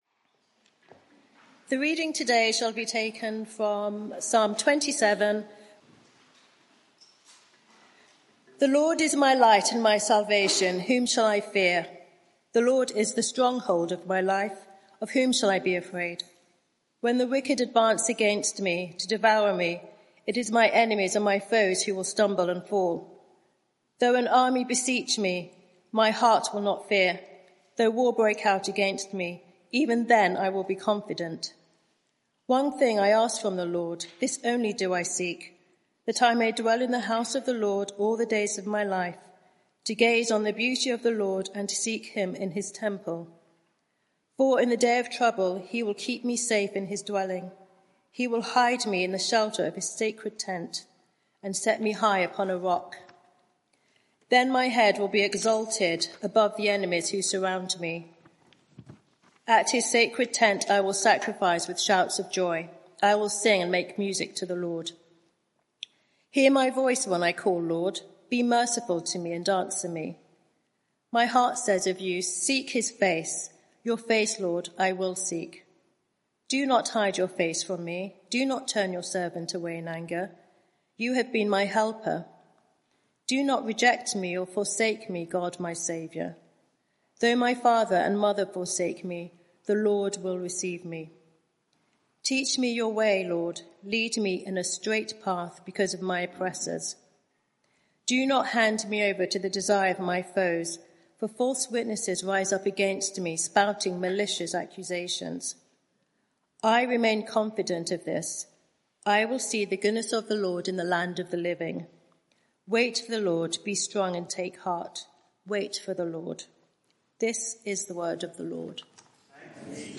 Media for 6:30pm Service on Sun 21st Jul 2024 18:30 Speaker
Passage: Psalm 27 Series: Telling God How I Feel Theme: When I’m under attack Sermon